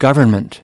16. government (n) /ˈɡʌvərnmənt/: chính phủ